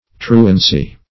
truancy - definition of truancy - synonyms, pronunciation, spelling from Free Dictionary
Truancy \Tru"an*cy\, n.